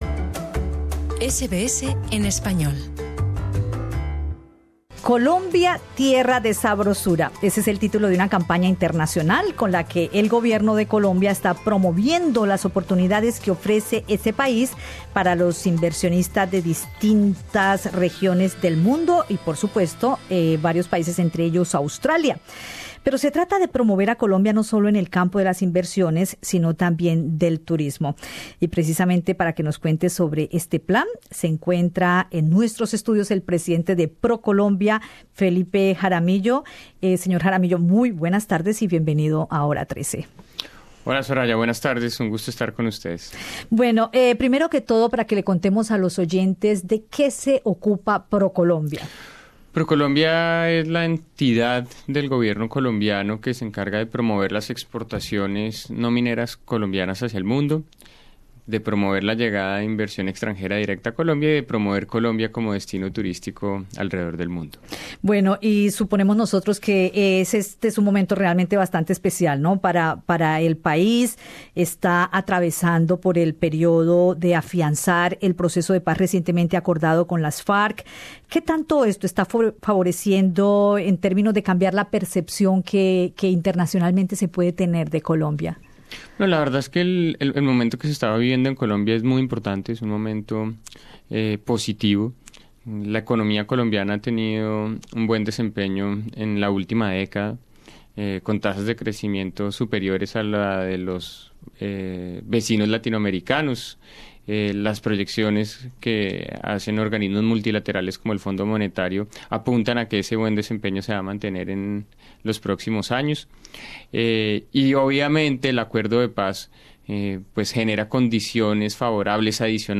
En Radio SBS conversamos con el presidente de Procolombia, Felipe Jaramillo, quien se encuentra en Australia, sobre los objetivos de un viaje que tiene la misión de acercar a más inversionistas australianos a las oportunidades en materia de negocios, sectores agroindustriales y por supuesto turísticos. Jaramillo también indicó la importancia del fortalecimiento del proceso de Paz en Colombia para generar la confianza de los inversionistas y viajeros internacionales al país.